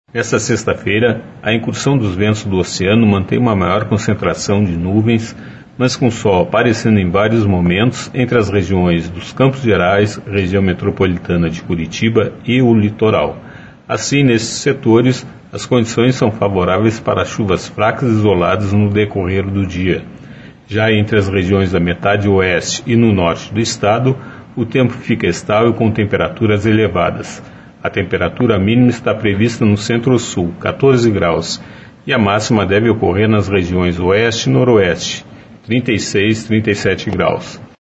[Sonora]